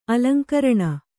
♪ alaŋkaraṇa